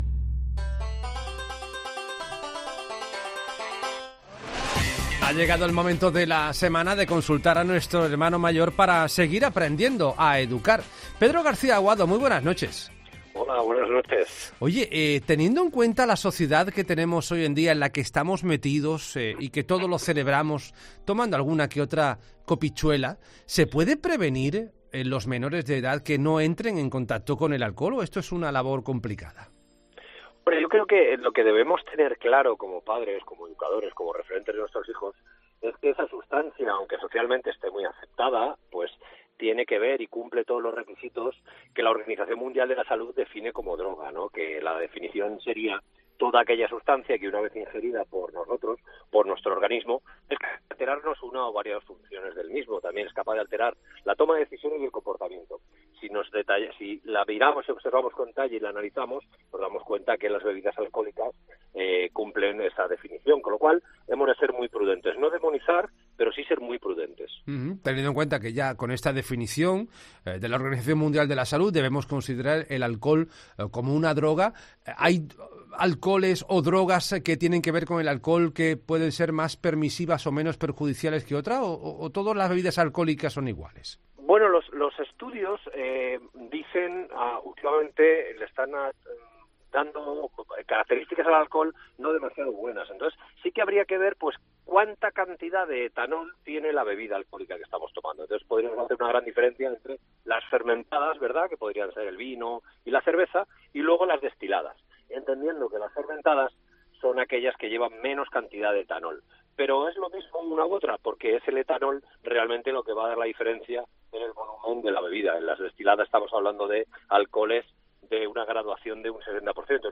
El intervencionista familiar de ‘La Noche de COPE’, Pedro García Aguado, sigue enseñándonos cómo aprender a educar a nuestros hijos.